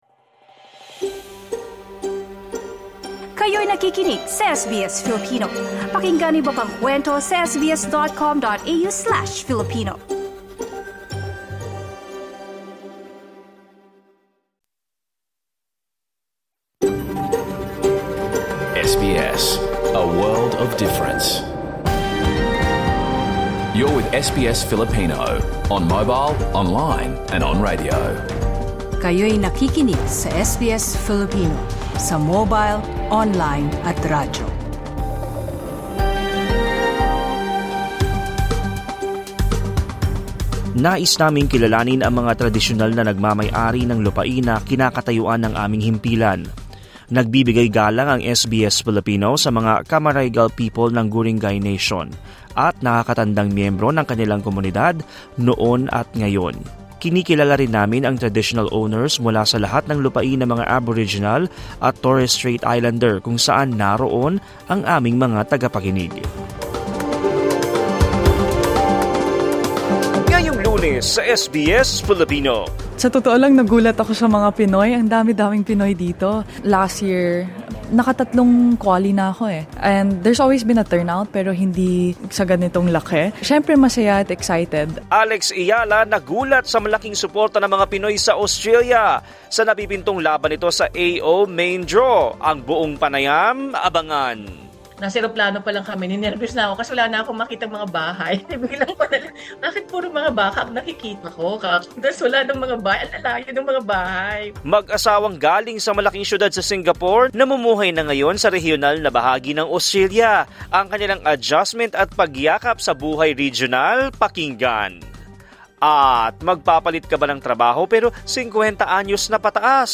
Key Points Alex Eala says she was surprised by the overwhelming support from Filipinos in Australia ahead of her upcoming match in the Australian Open main draw. Catch the full interview!